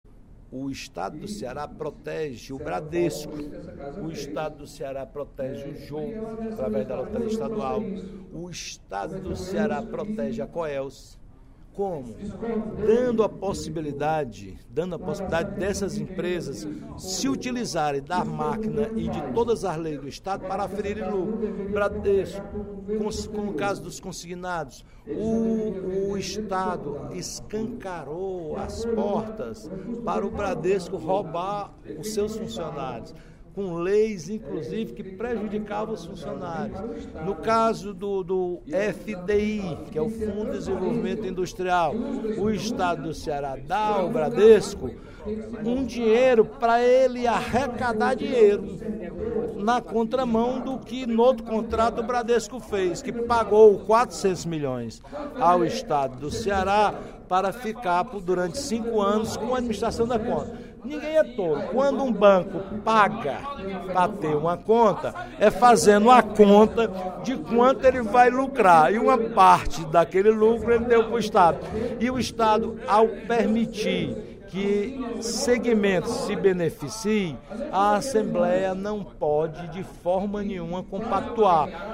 O deputado Roberto Mesquita (PV) criticou, no primeiro expediente da sessão plenária desta sexta-feira (25/09), o Governo do Estado, que, segundo ele, favorece o aumento do lucro de grandes empresas por meio de leis aprovadas pelo Legislativo.